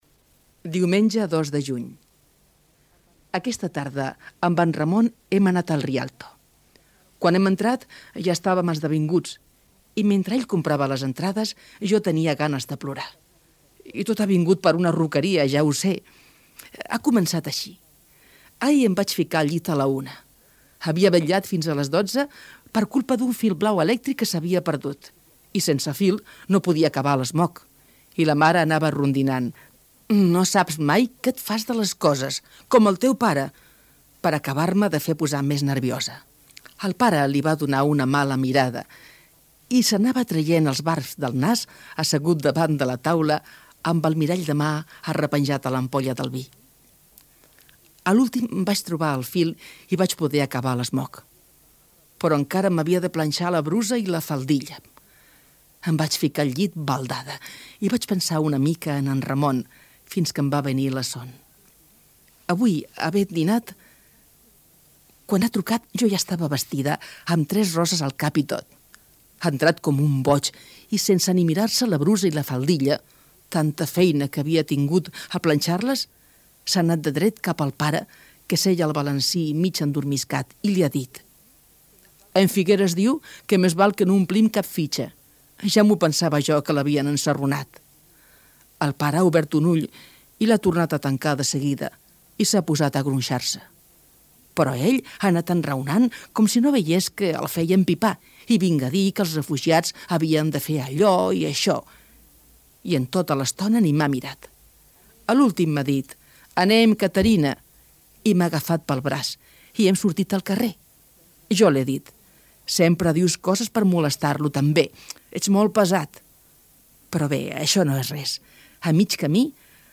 Entreteniment
Presentador/a
FM